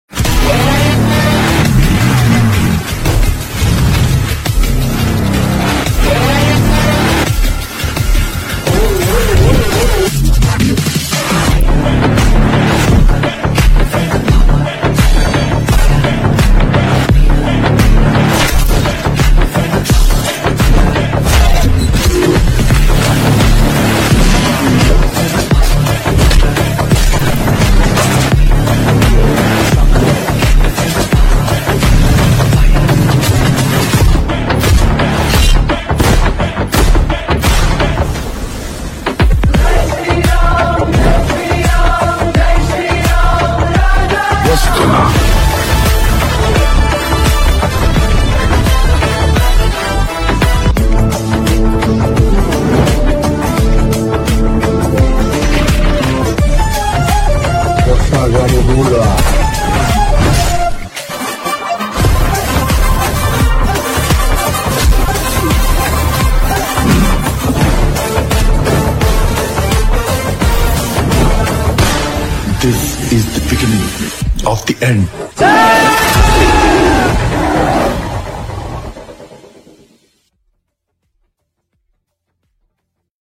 Categories BGM Ringtones